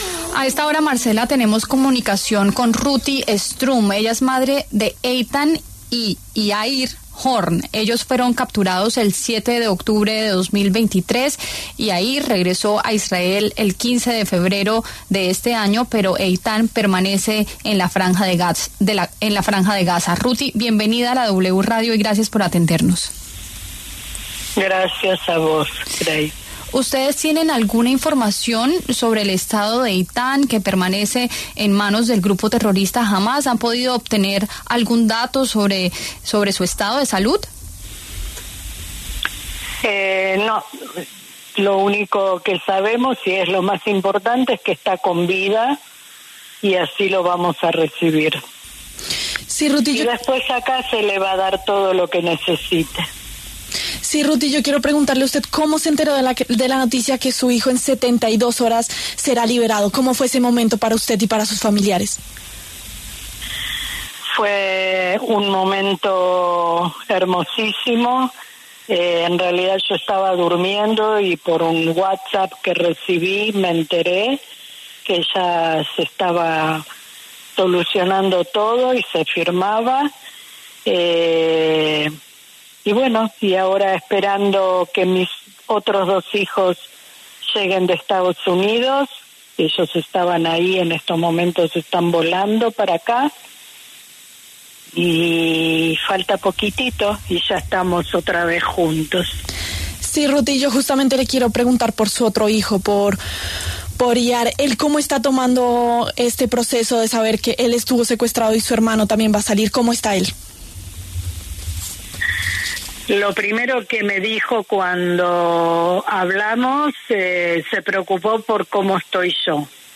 pasó por los micrófonos de La W para contar más detalles de este caso y de la firma de la primera fase del acuerdo de paz en Gaza.